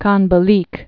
(känbə-lēk)